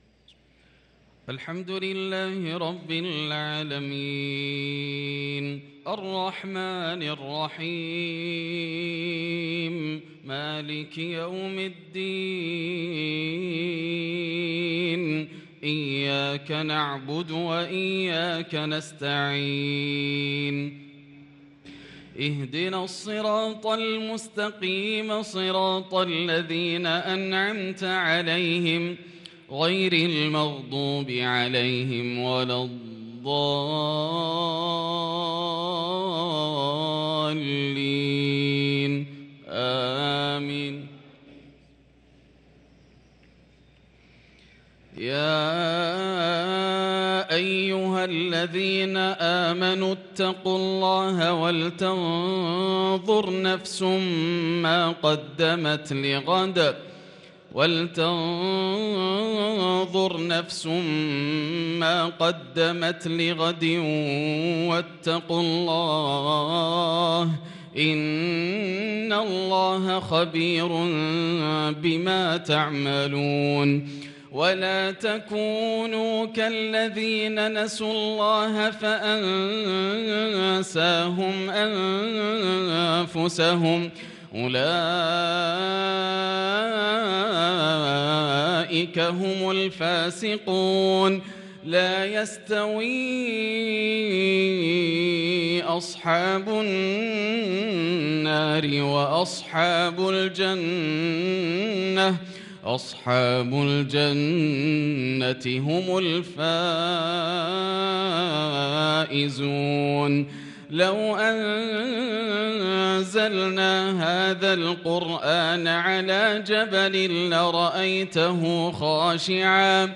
صلاة المغرب للقارئ ياسر الدوسري 13 رمضان 1443 هـ